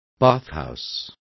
Complete with pronunciation of the translation of bathhouse.